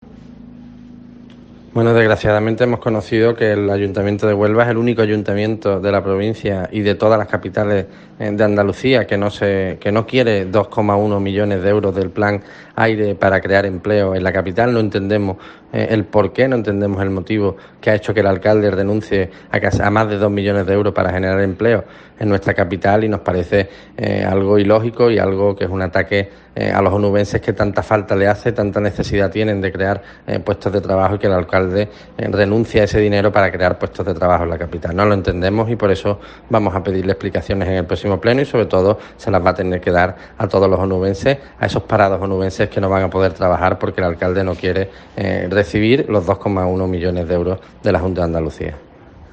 Guillermo García de Longoria, portavoz de Cs en el Ayto Huelva